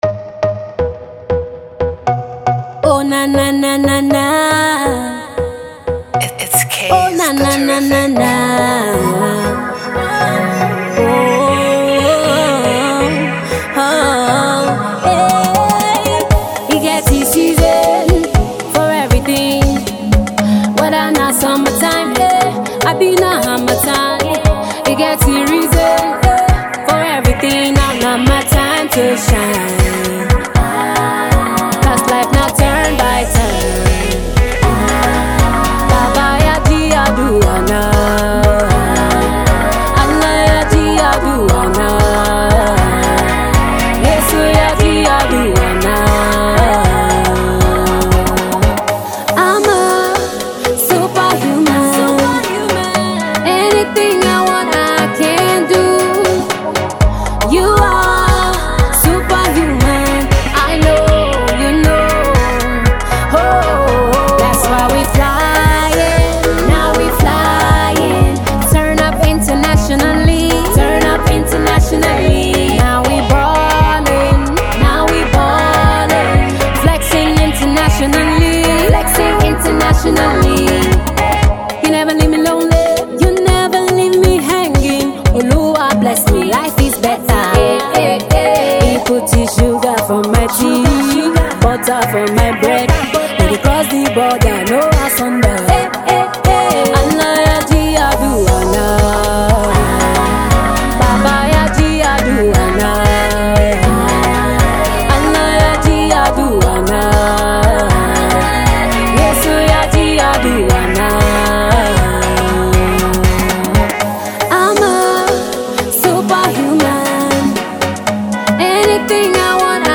Nigerian female Afro pop singer